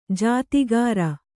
♪ jāti gāra